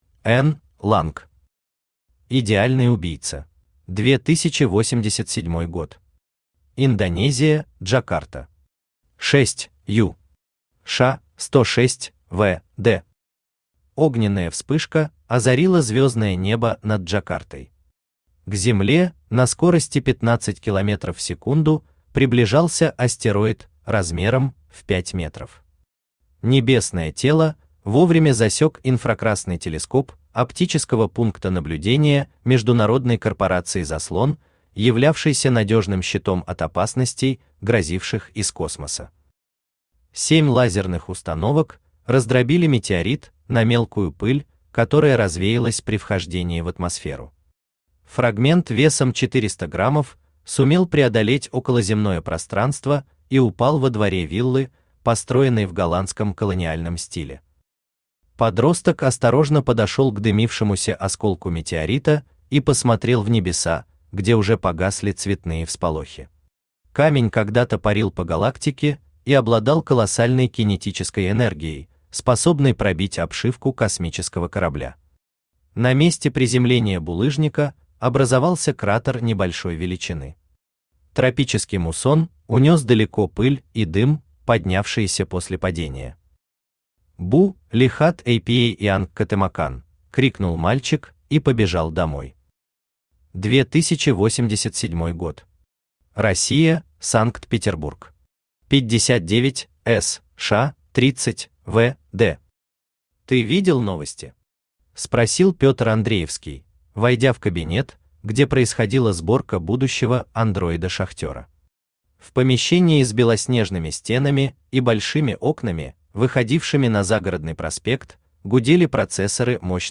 Аудиокнига Идеальный убийца | Библиотека аудиокниг
Aудиокнига Идеальный убийца Автор Н. Ланг Читает аудиокнигу Авточтец ЛитРес.